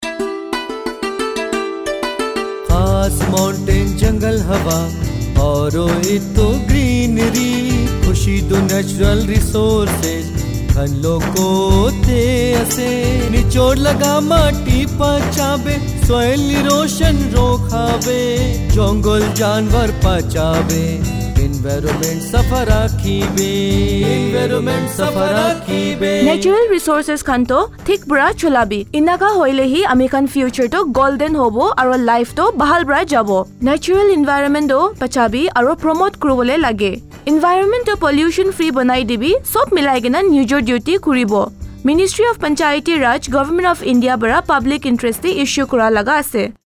174 Fundamental Duty 7th Fundamental Duty Preserve natural environment Radio Jingle Nagamese